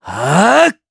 Neraxis-Vox_Casting2_jp.wav